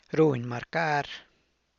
runy marcar[ruhny marcaar]